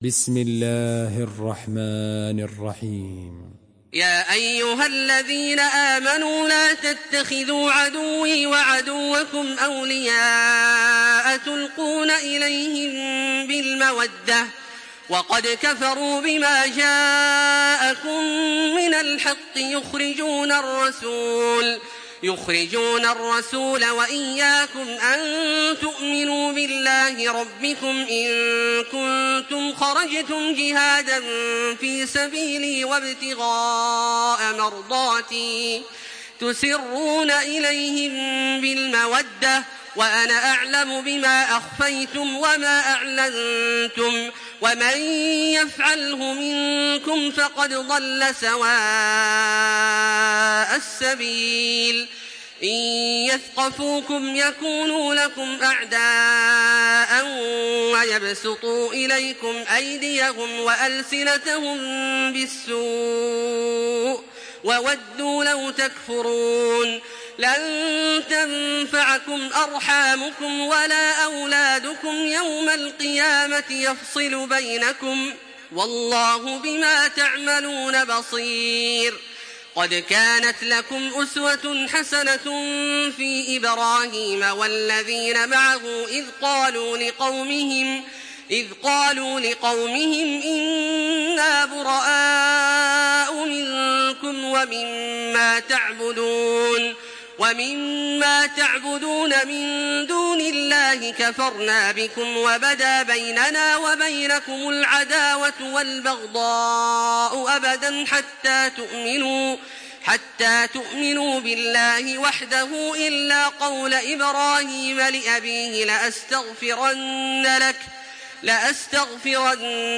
سورة الممتحنة MP3 بصوت تراويح الحرم المكي 1426 برواية حفص عن عاصم، استمع وحمّل التلاوة كاملة بصيغة MP3 عبر روابط مباشرة وسريعة على الجوال، مع إمكانية التحميل بجودات متعددة.
مرتل